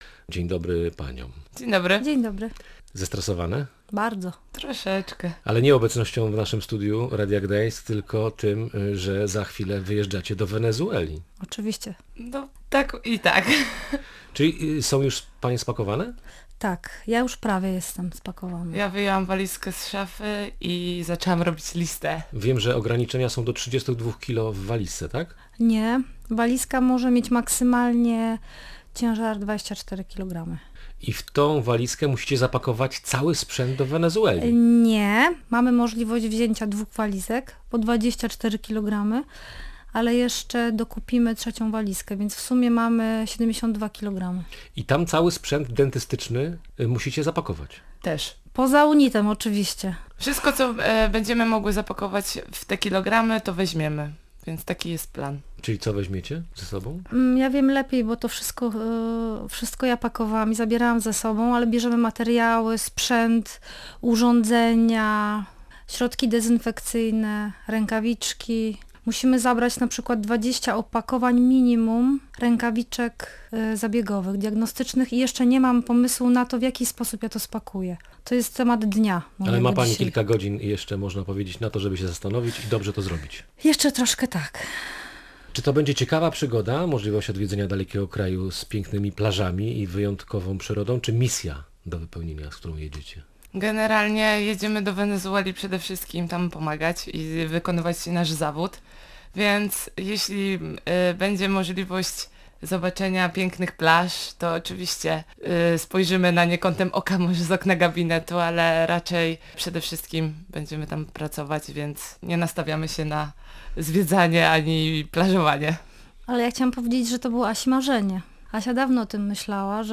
/audio/dok3/wenezuela.mp3 Tagi: audycje Gość Dnia Radia Gdańsk